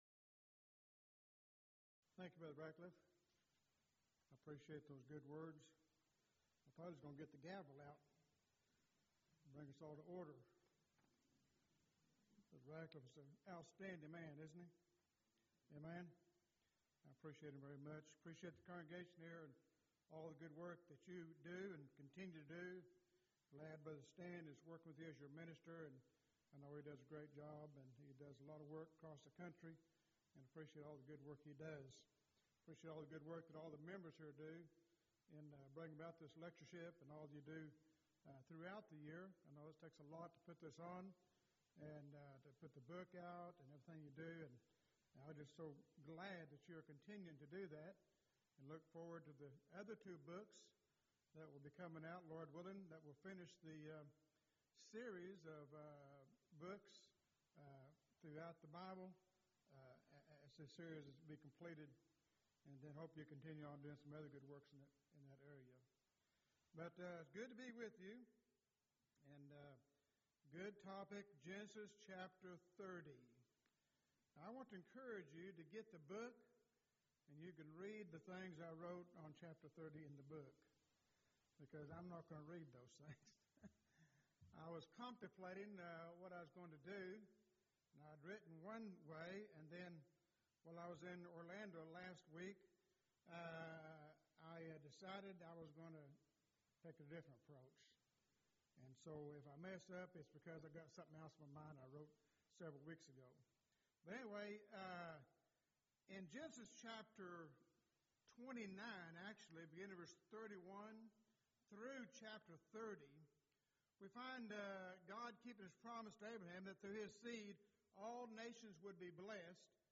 Event: 16th Annual Schertz Lectures
lecture